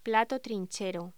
Locución: Plato trinchero
voz